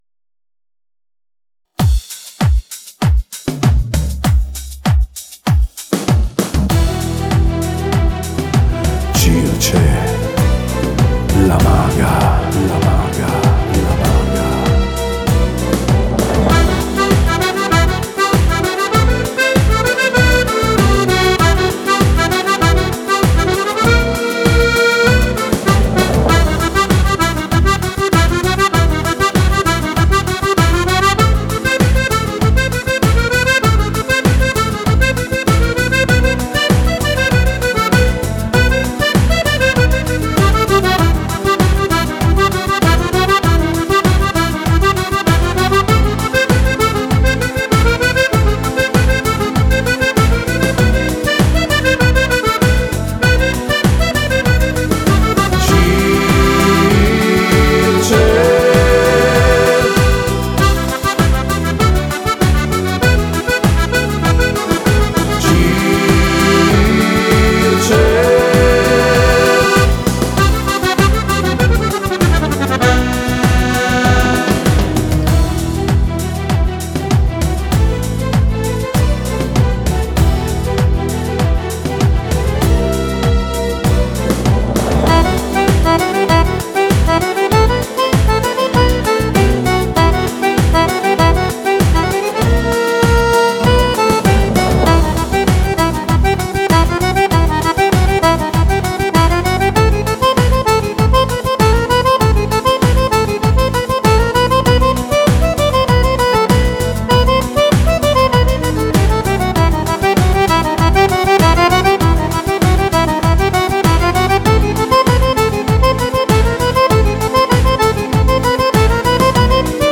Cumbia
Cumbia per Fisarmonica